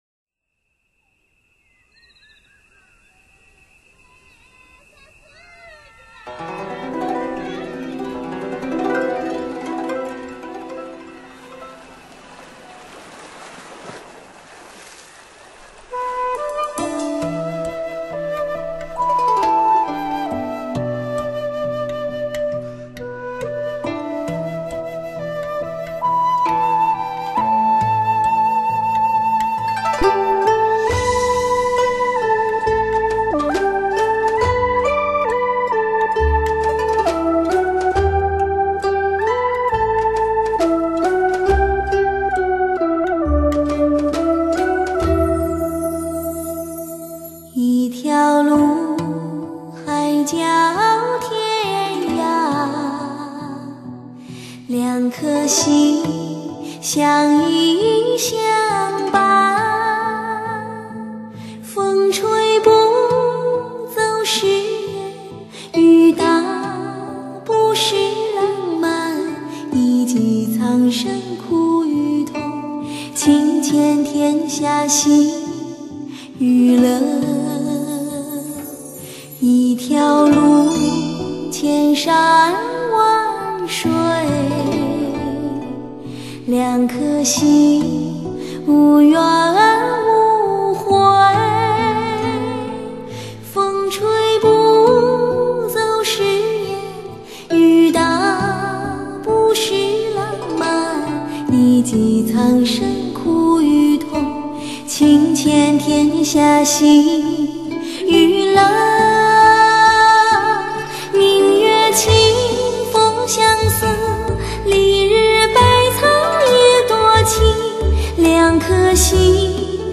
3D音效HI-FINEW AGE车载
天籁女声天碟
清新音色，磁性迷人歌喉，一声声，一首